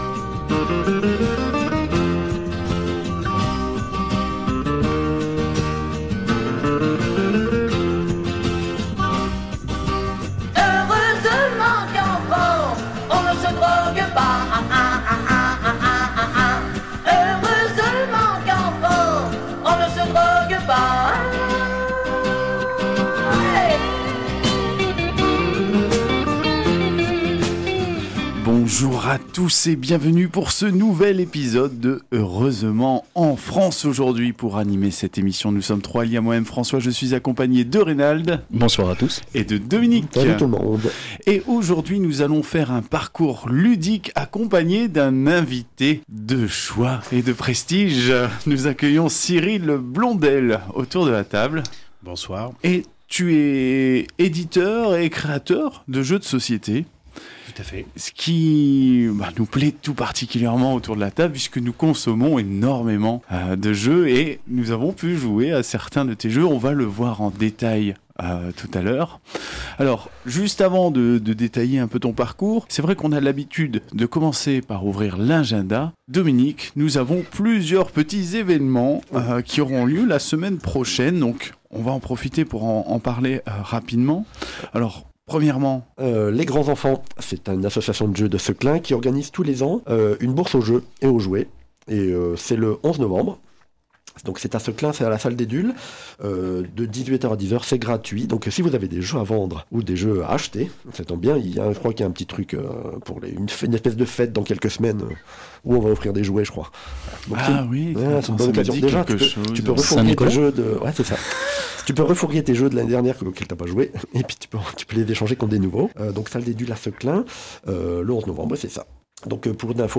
Au sommaire de cet épisode diffusé le 7 novembre 2021 sur Radio Campus 106.6 :